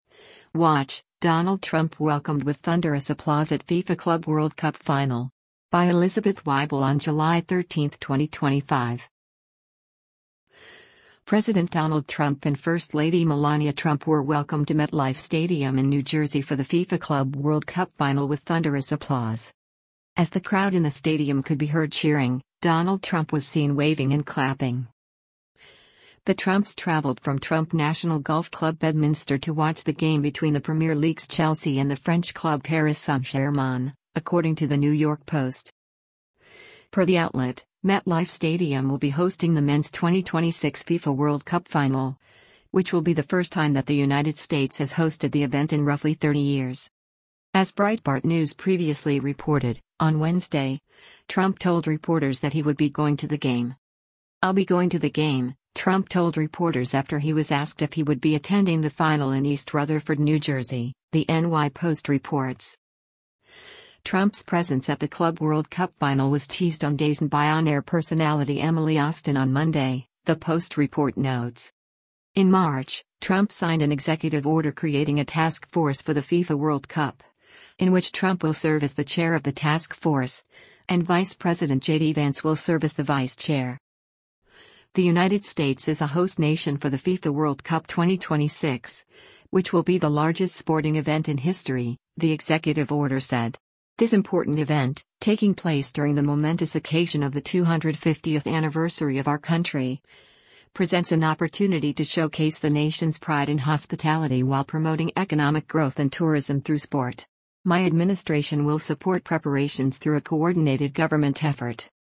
WATCH: Donald Trump Welcomed with Thunderous Applause at FIFA Club World Cup Final
President Donald Trump and First Lady Melania Trump were welcomed to MetLife Stadium in New Jersey for the FIFA Club World Cup final with thunderous applause.
As the crowd in the stadium could be heard cheering, Donald Trump was seen waving and clapping.